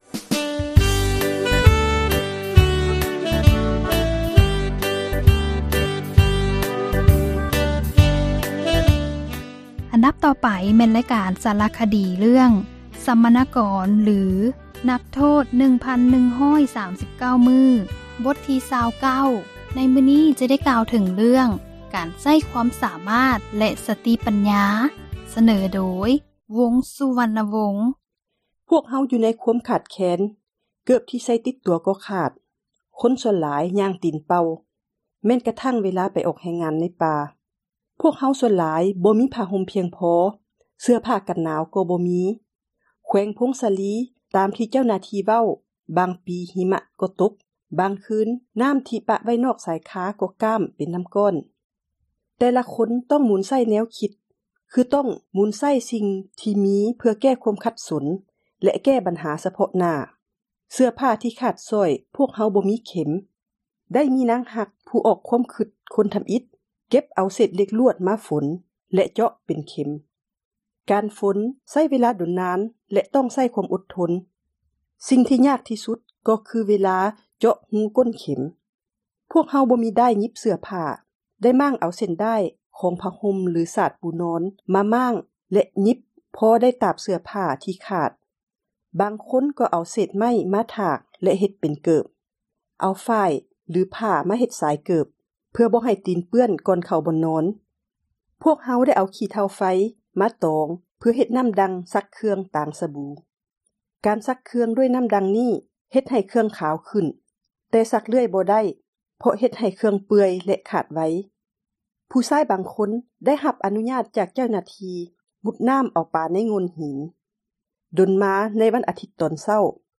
ສາຣະຄະດີ ເຣື້ອງ ສັມມະນາກອນ ຫຼື ນັກໂທດ 1139 ມື້ ບົດທີ 29. ໃນມື້ນີ້ ຈະກ່າວເຖິງ ການໃຊ້ ຄວາມສາມາດ ແລະ ສະຕິ ປັນຍາ.